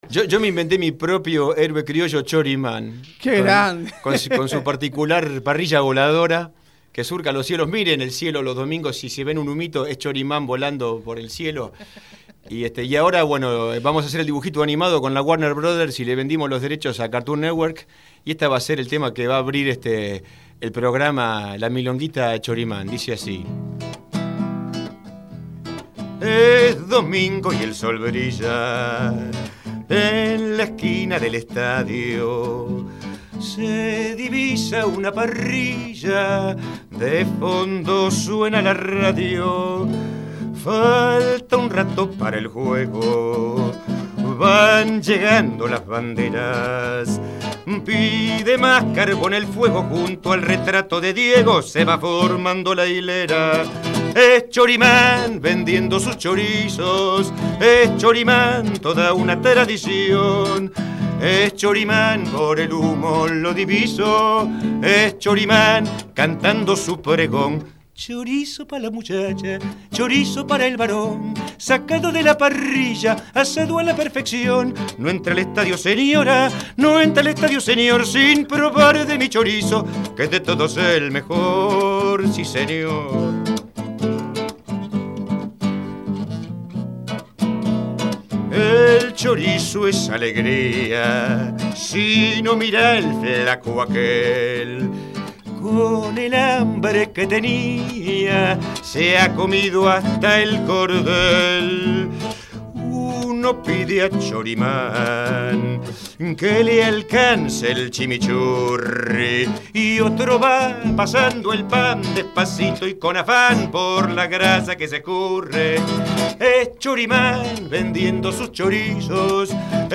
cantó tres tangazos…